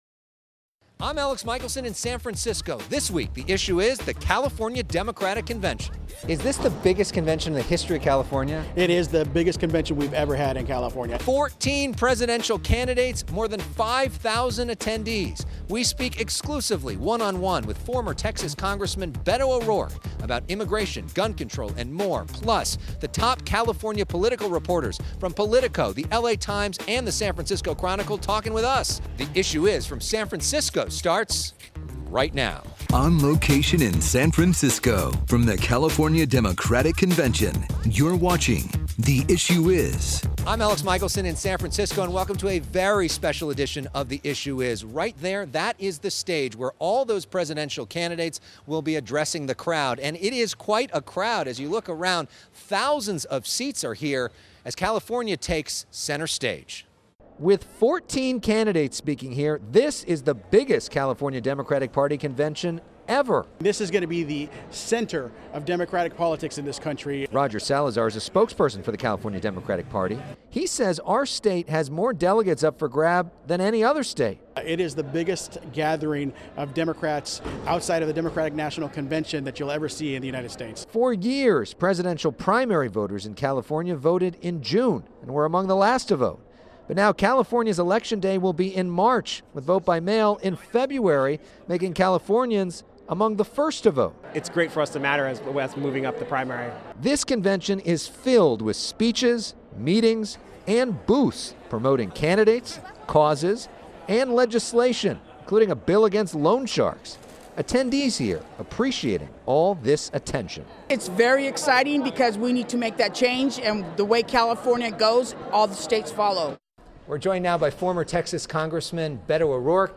This week, a special edition of “The Issue Is:” from the California Democratic Convention in San Francisco.